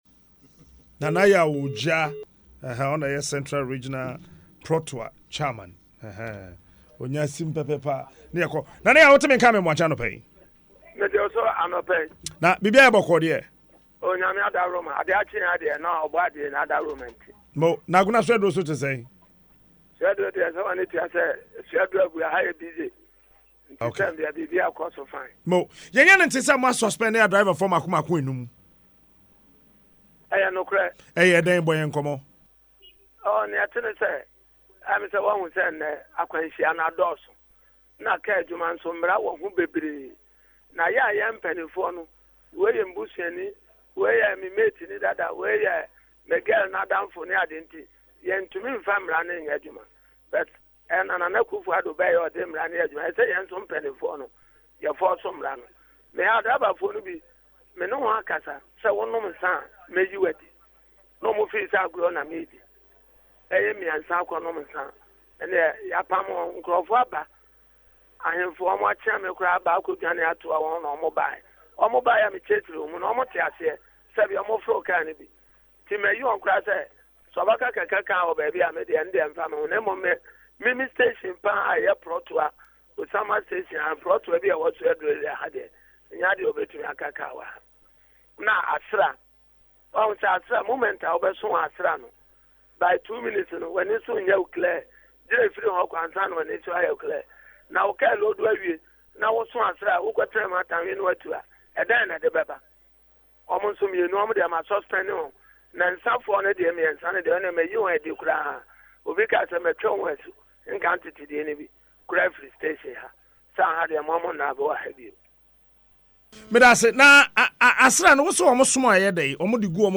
He told Adom FM’s FM’s “Dwaso Nsem” on Friday that the disciplinary measures were taken to sanitize the driving profession and also promote discipline on the roads.